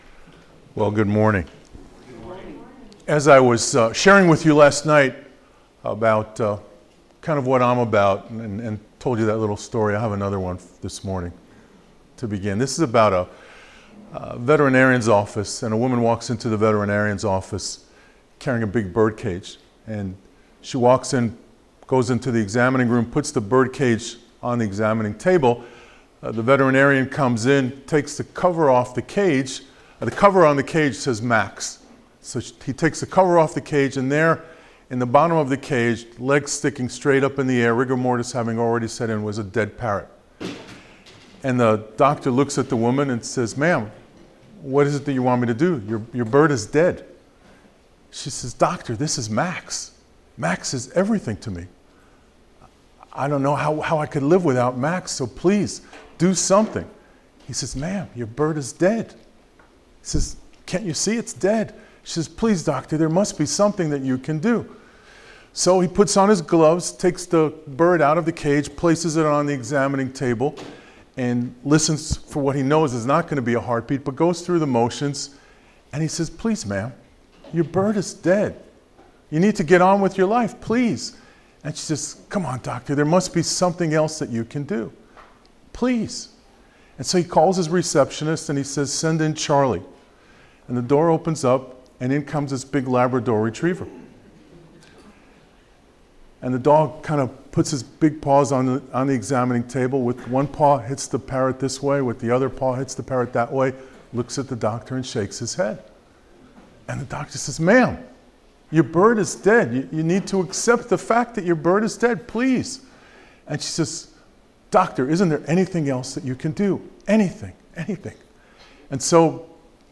Sermons - Central Baptist Church Owasso